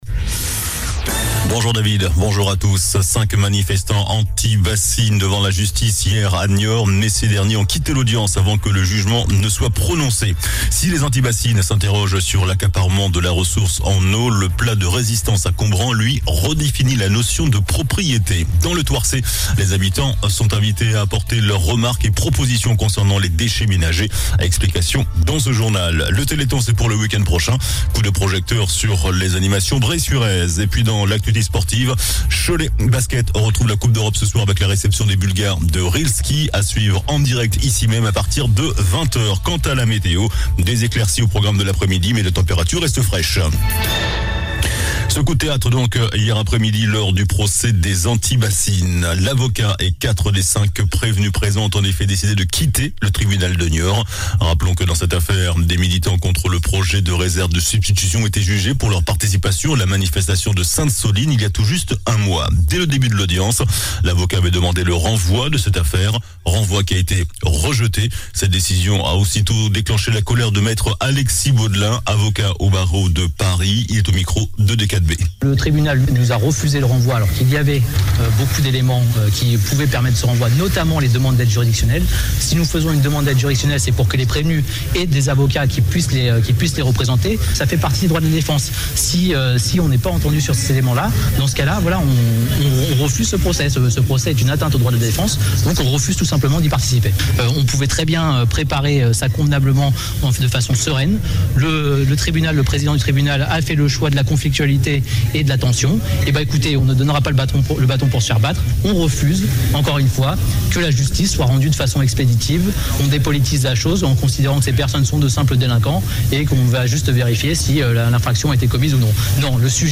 JOURNAL DU MARDI 29 NOVEMBRE ( MIDI )